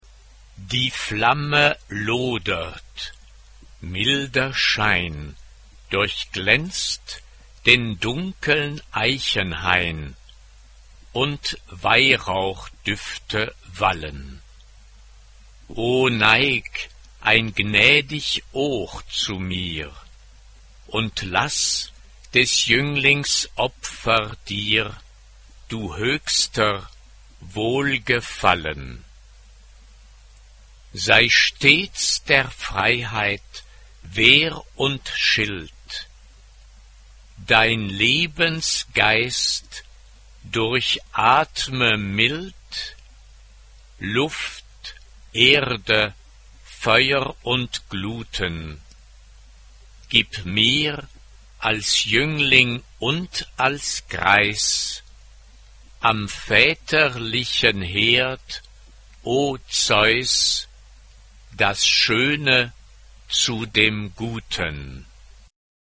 SATB (4 voix mixtes) ; Partition complète.
Tonalité : mi bémol majeur